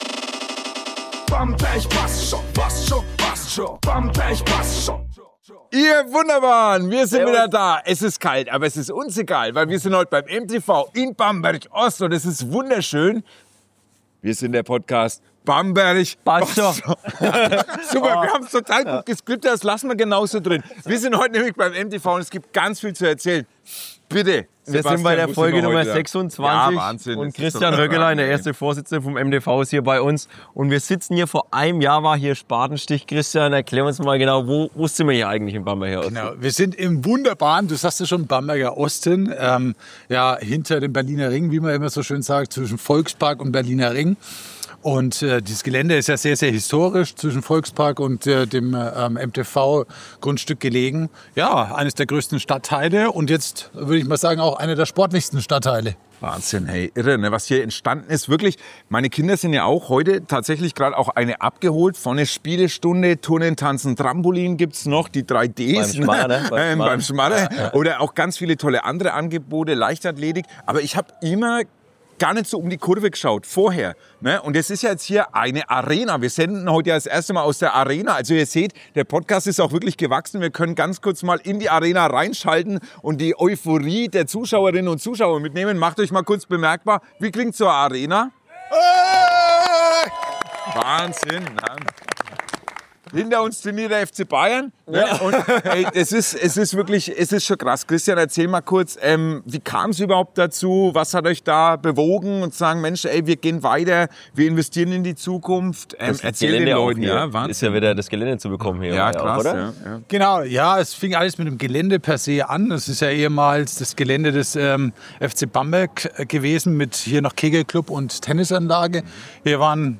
Beschreibung vor 3 Monaten In Folge 026 von Bamberg bassd scho! sind wir zu Gast beim MTV Bamberg im Bamberger Osten – genauer gesagt in der neuen Outdoor-Arena zwischen Volkspark und Berliner Ring.